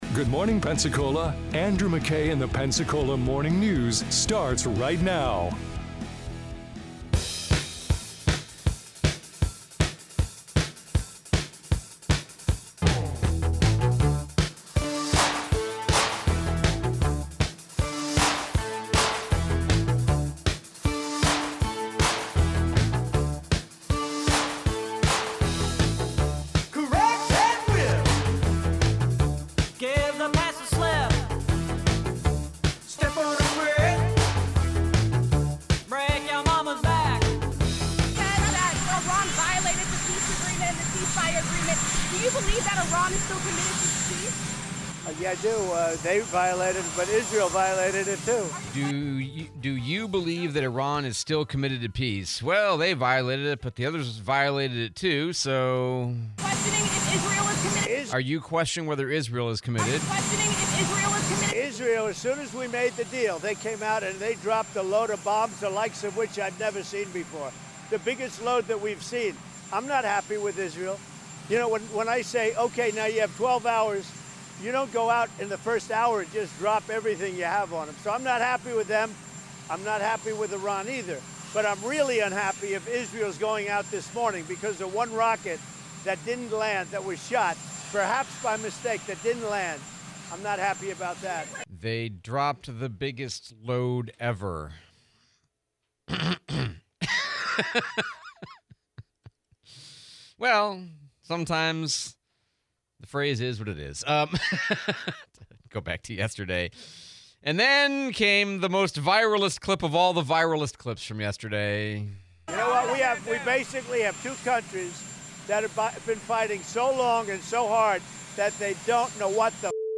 Trump Press conference, Replay os Escambia County Sheriff Chip Simmons